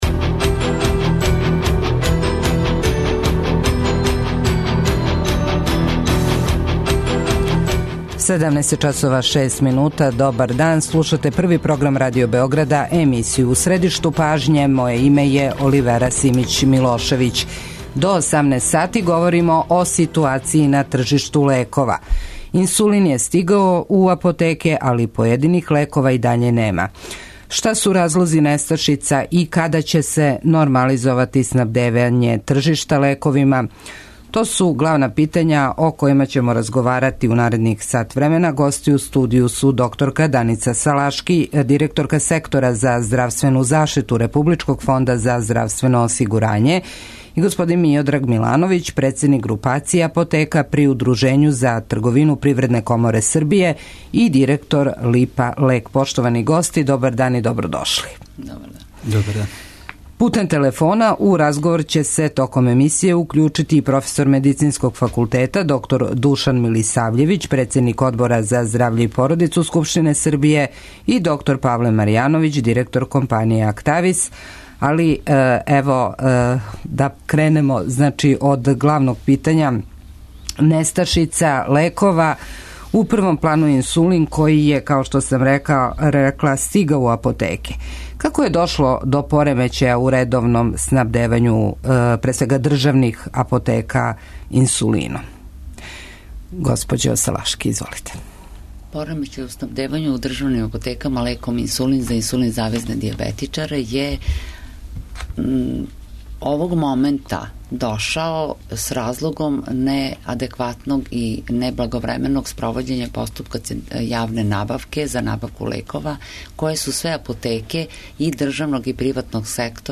Шта су разлози несташица, када ће се нормализовати снабдевање тржишта лековима, да ли лекови којих нема у државним апотекама могу на рецепт да се набаве у приватним? Гости у студију су: